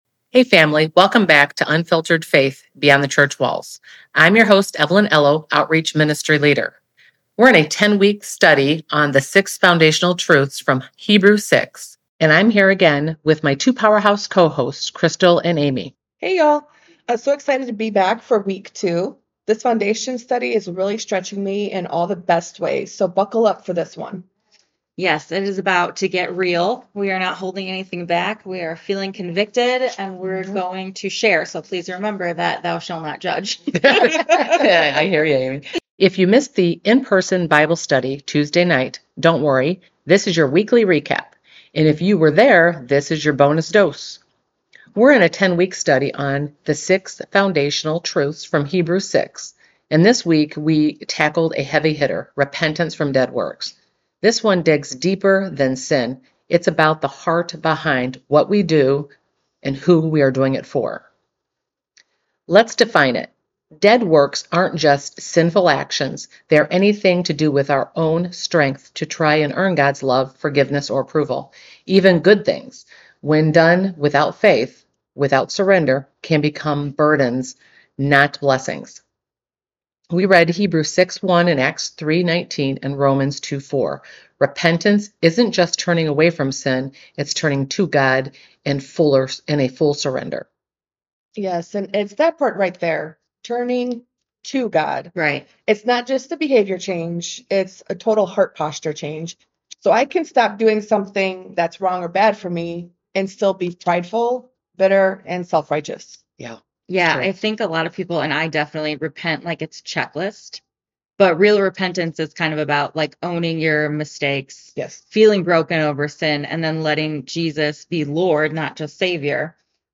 unfiltered roundtable conversation